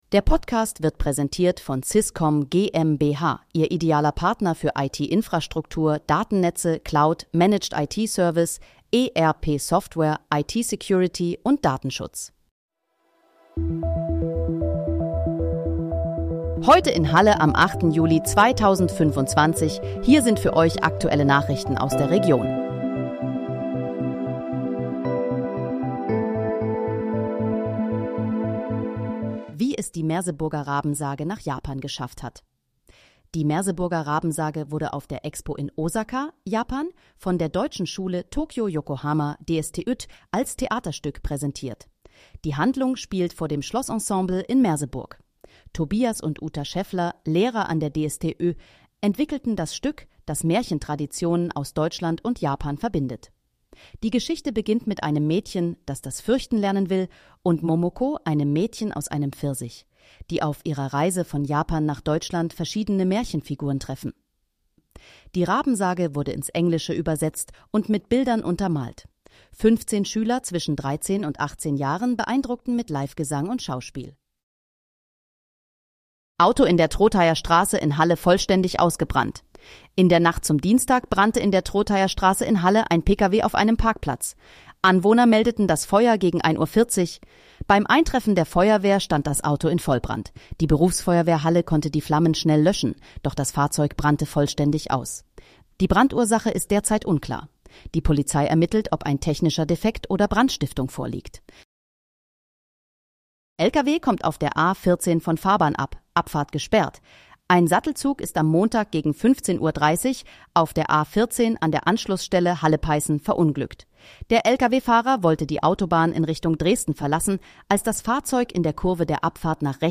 Heute in, Halle: Aktuelle Nachrichten vom 08.07.2025, erstellt mit KI-Unterstützung
Nachrichten